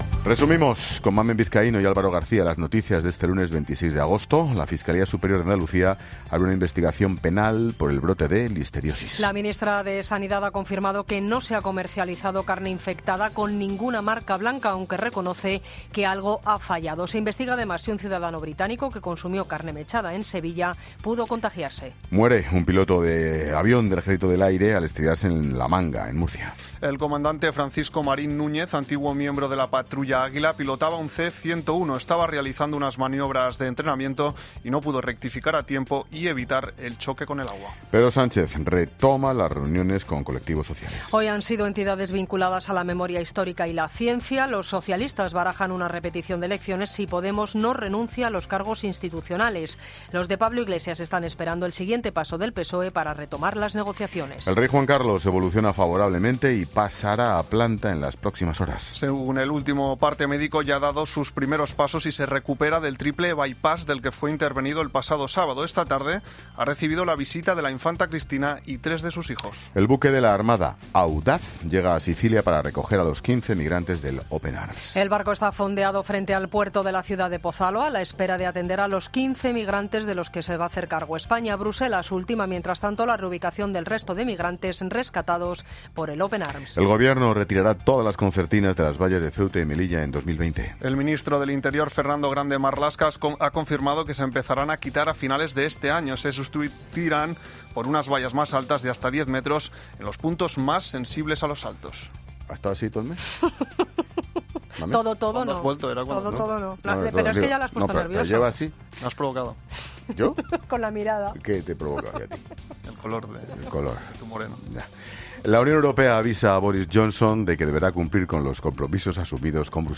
Boletín de noticias Cope del 26 de agosto a las 20.00 horas